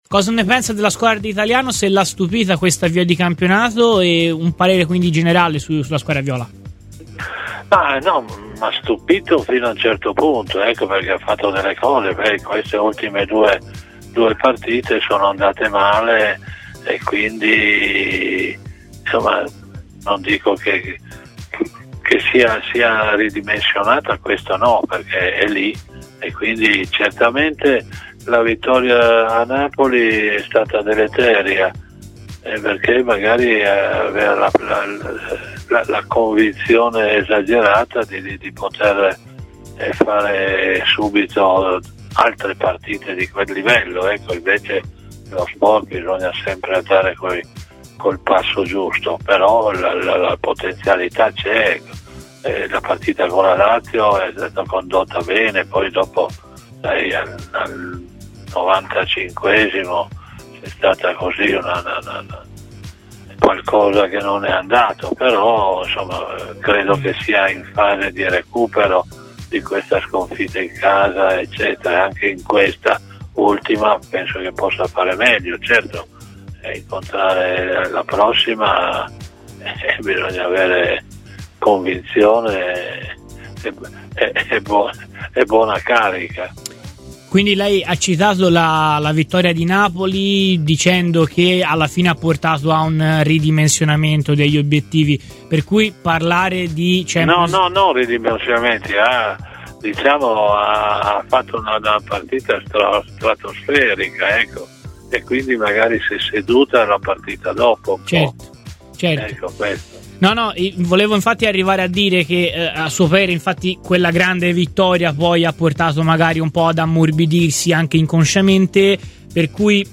Dino Zoff, ex portiere della Nazionale e campione del mondo nel 1982, nonché bandiera bianconera ma anche ex allenatore viola, è stato intervistato da Radio FirenzeViola in vista della partita di domenica tra Fiorentina e Juventus